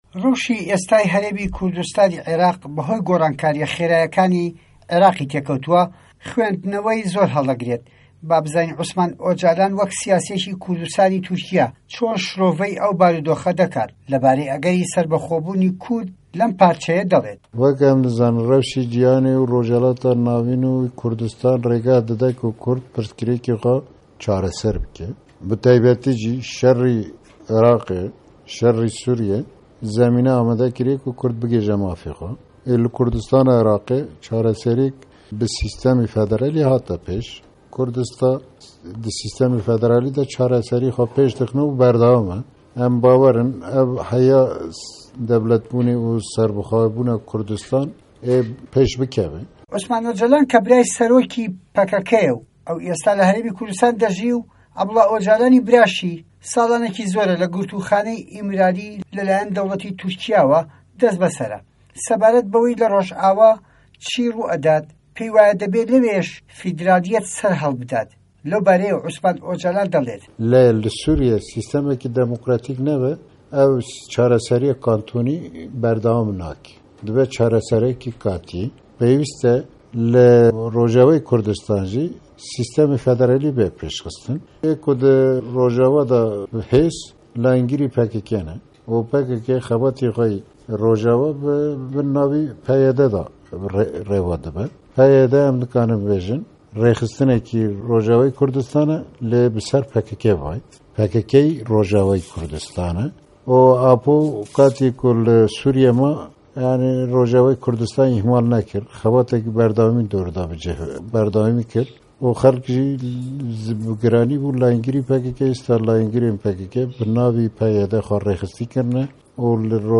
Di hevpeyvîneke taybet de ligel Dengê Amerîkayê, siyasetvanê Kurd yê navdar Osman Ocalan behsa rewşa li Kurdistanê dike.
Hevpeyvîn bi Osman Ocalan re